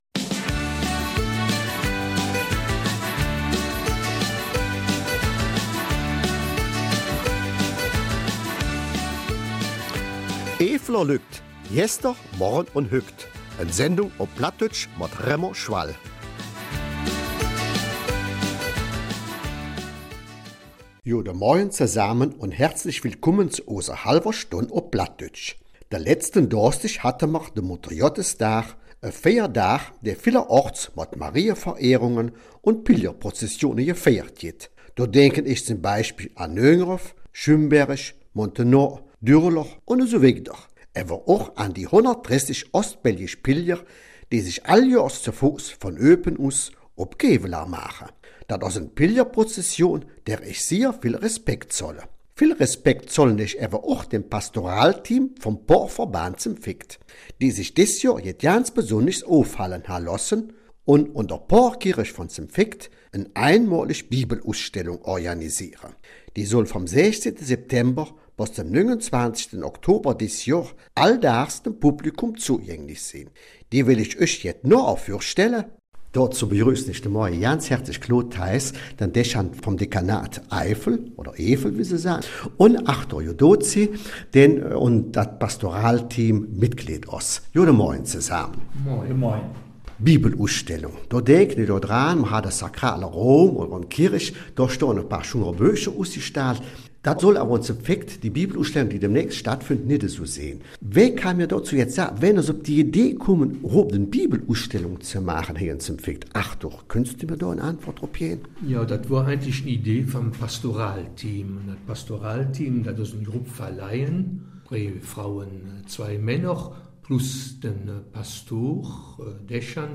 Eifeler Mundart: Ausstellung ''Bibel erleben'' in St. Vith